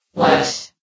S.P.L.U.R.T-Station-13/sound/vox_fem/what.ogg
* New & Fixed AI VOX Sound Files